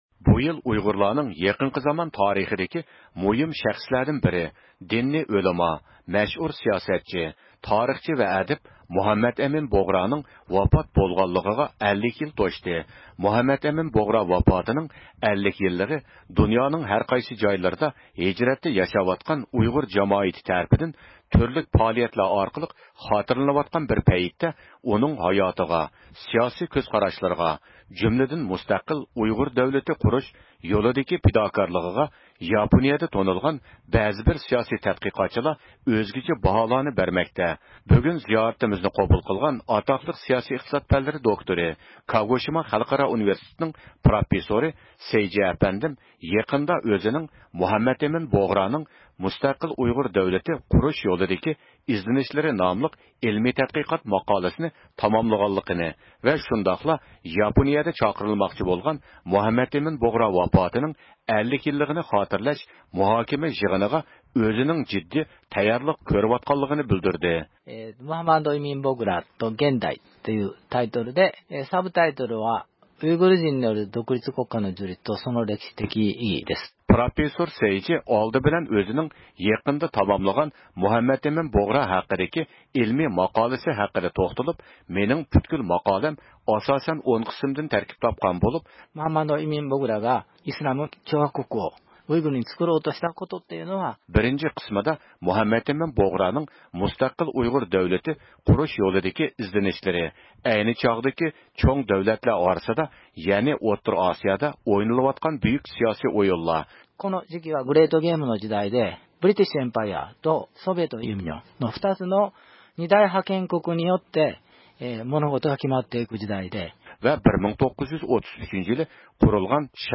مۇخبىرنىڭ زىيارىتىنى قوبۇل قىلماقتا